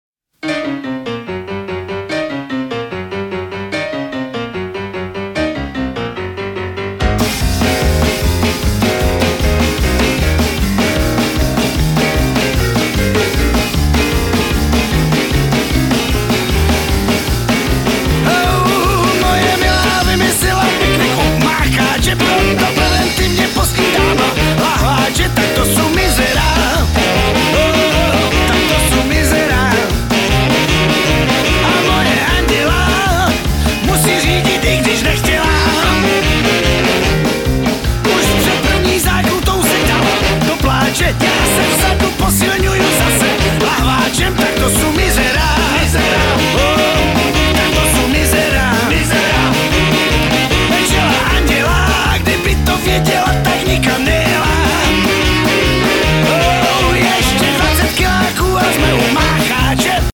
hosty na dechové nástroje.